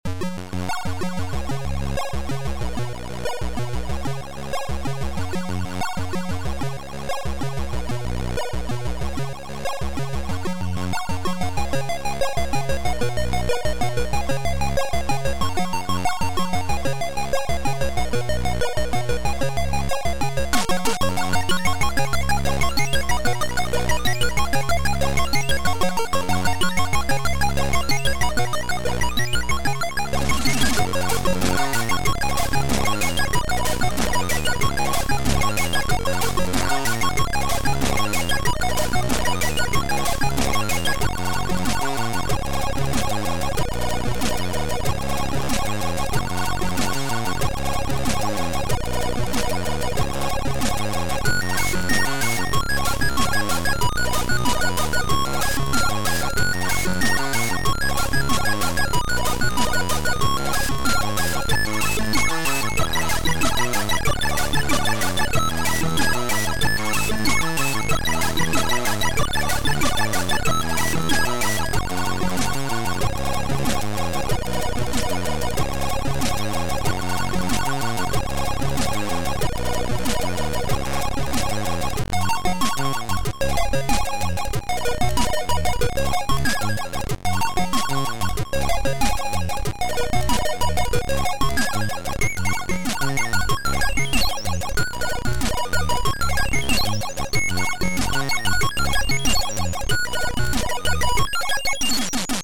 • An allocated channels for playing by default is ABC
• Sound chip AY-3-8912 / YM2149